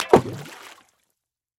Звуки бульк
Звук лопающихся пузырей при опускании большого объекта в воду